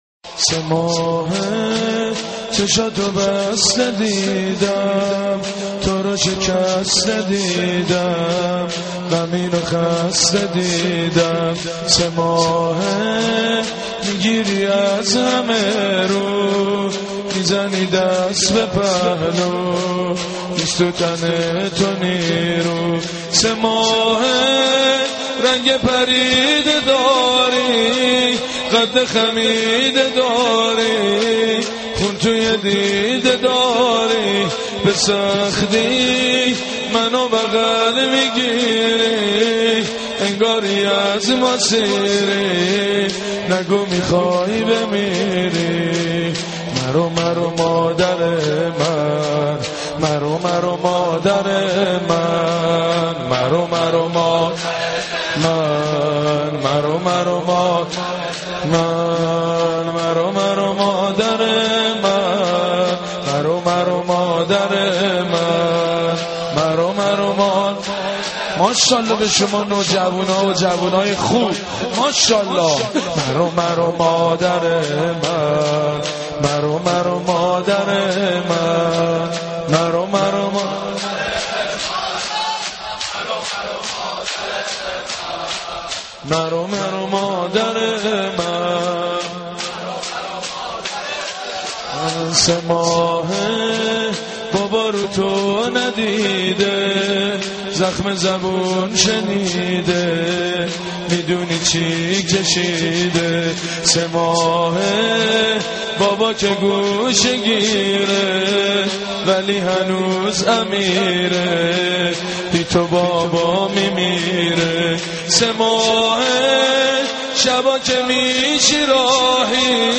سینه‌زنی